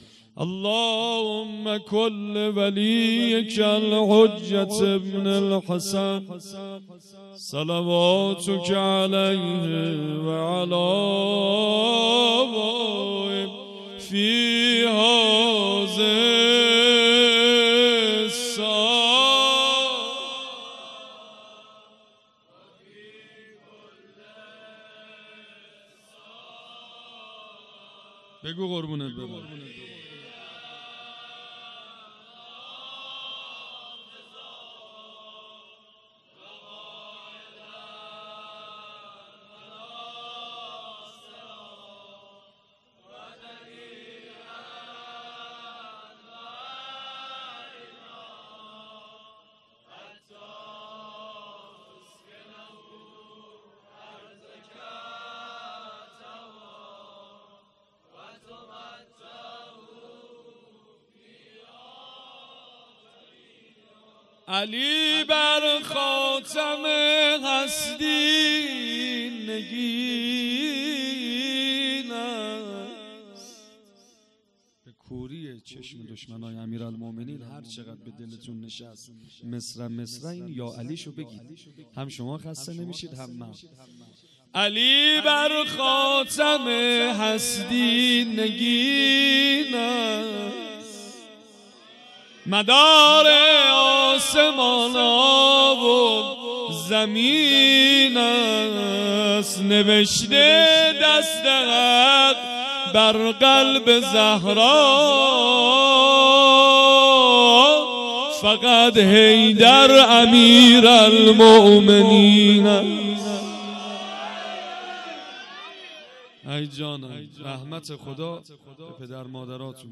مدح خوانی
شب اول جشن عید غدیر ۱۴۰۴